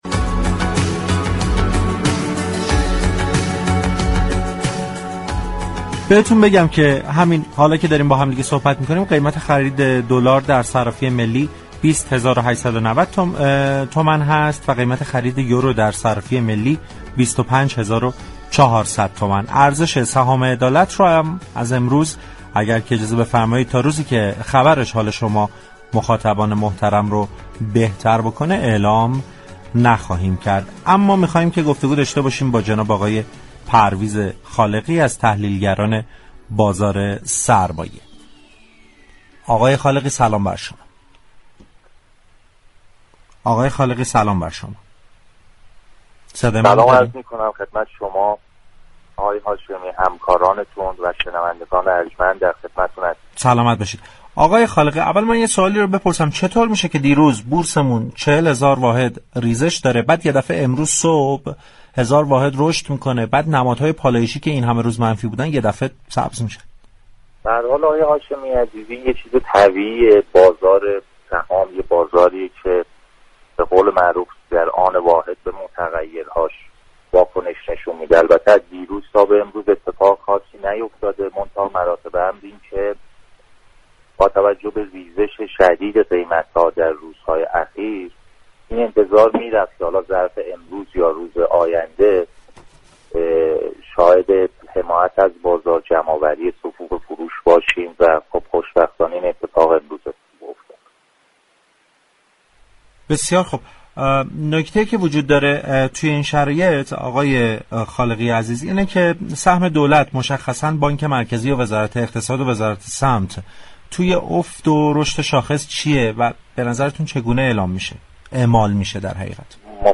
در گفتگو با برنامه بازار تهران